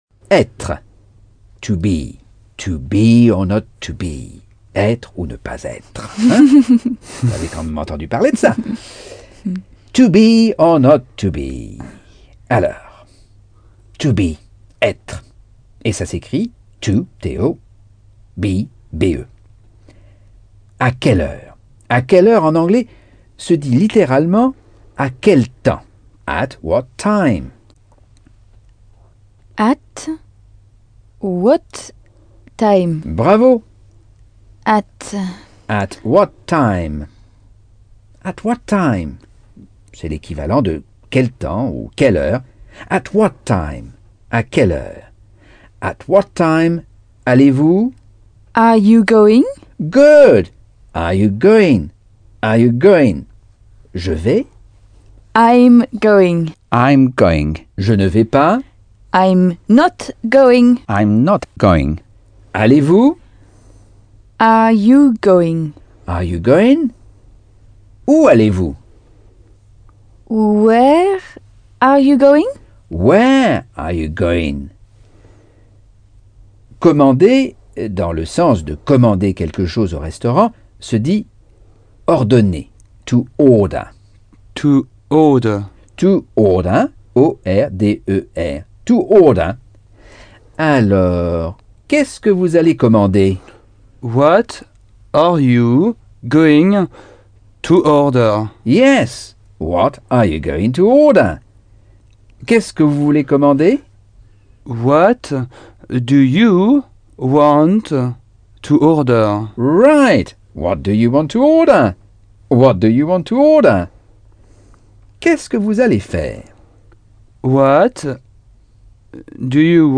Leçon 6 - Cours audio Anglais par Michel Thomas